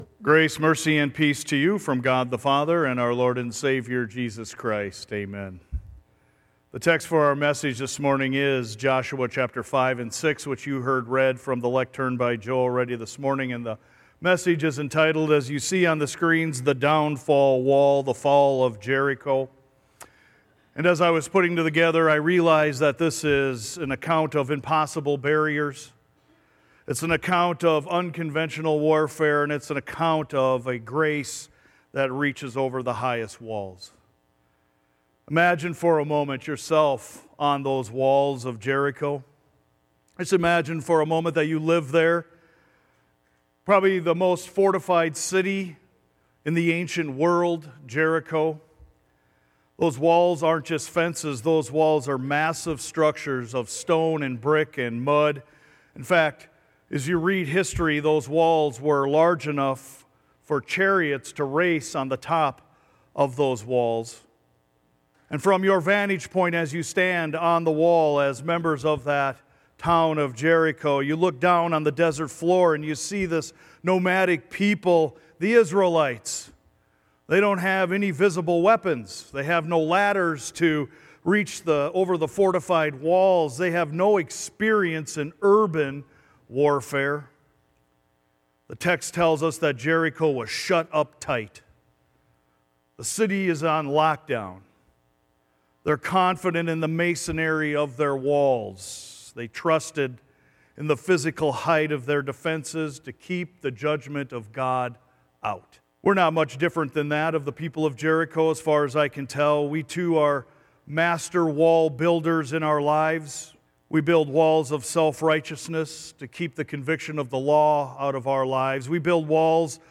SERMON-MARCH-1-2026.mp3